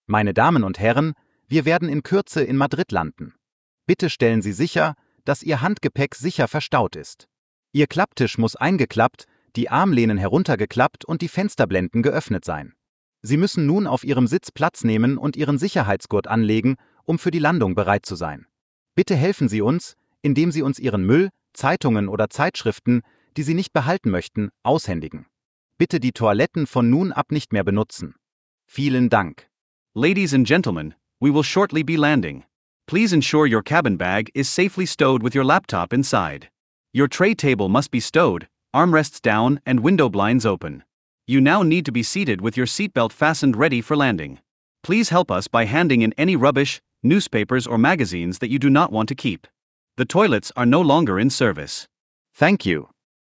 DescentSeatbelts.ogg